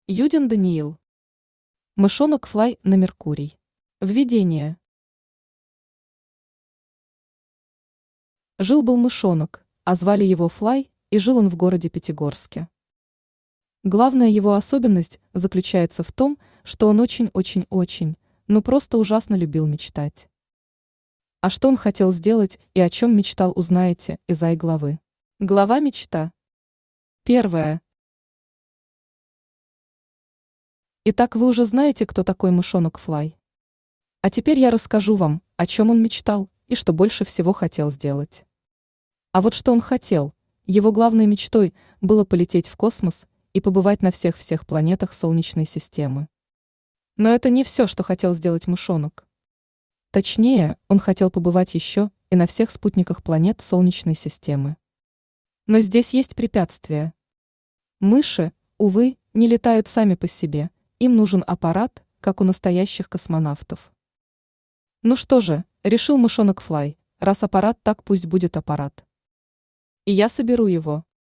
Аудиокнига Мышонок Флай на Меркурии | Библиотека аудиокниг
Aудиокнига Мышонок Флай на Меркурии Автор Юдин Алексеевич Даниил Читает аудиокнигу Авточтец ЛитРес.